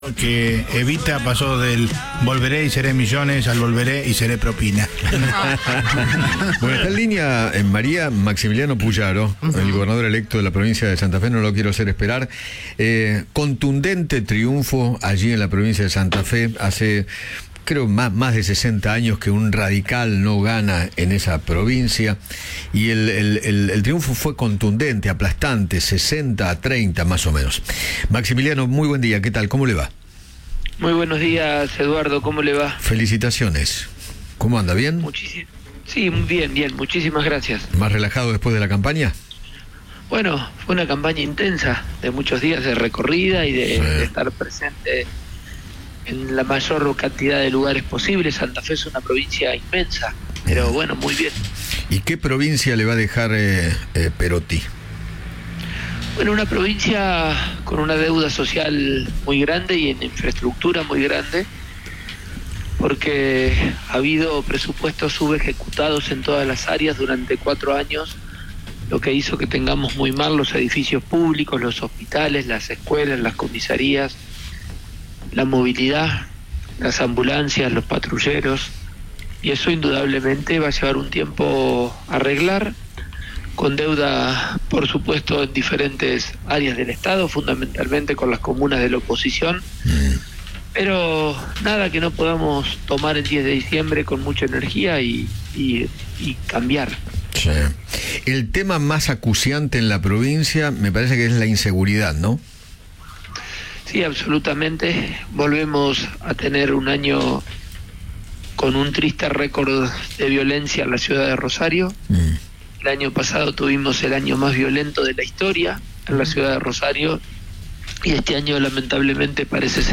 Maximilaino Pullaro, gobernador electo de Santa Fe, conversó con Eduardo Feinmann sobre su victoria en las elecciones provinciales del domingo.